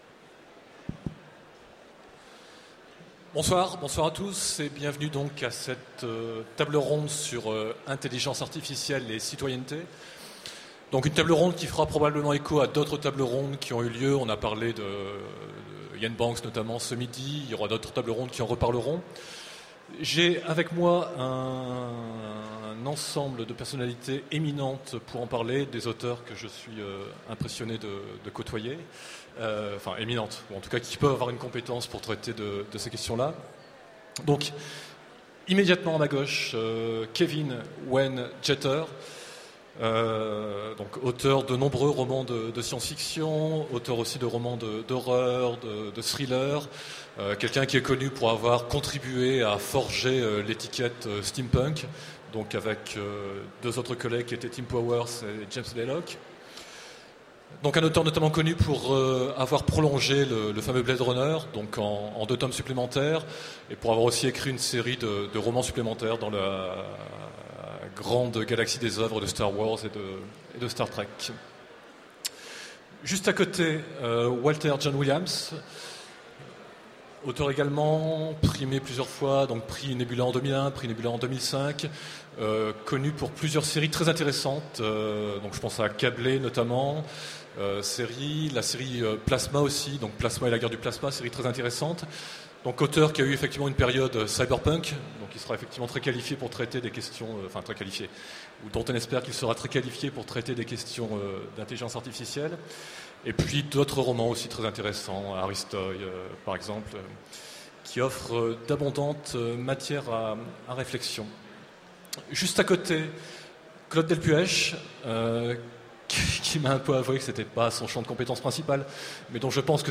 Mots-clés Droit Intelligence artificielle Conférence Partager cet article